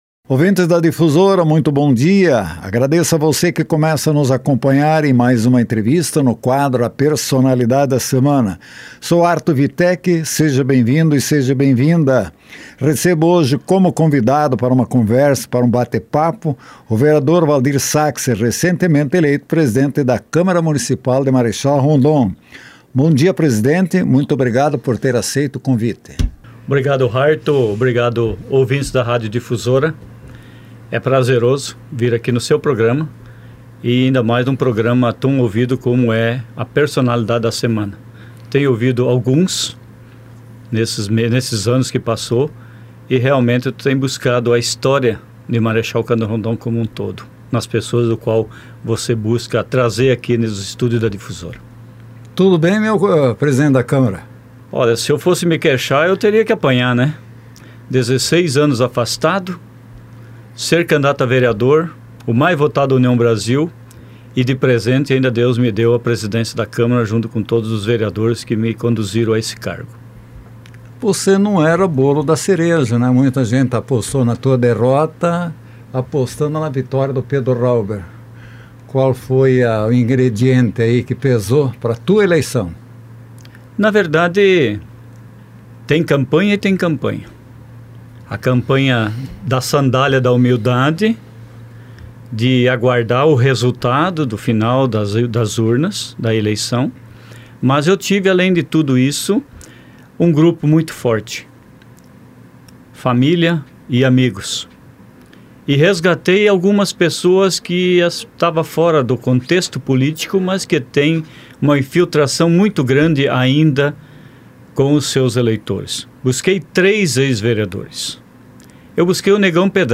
Vereador Valdir Sackser, presidente da Câmara Municipal de Marechal Cândido Rondon foi o entrevistado em A Personalidade da Semana